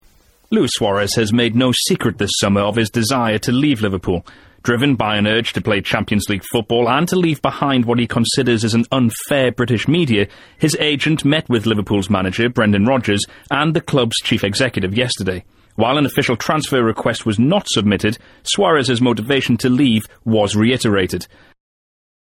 【英音模仿秀】苏亚雷斯愿加盟阿森纳 想离队因渴望踢欧冠 听力文件下载—在线英语听力室